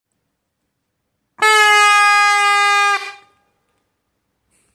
Écoute des signaux d’alarme👂📢🔥🚒⛈🔐
• la corne de brume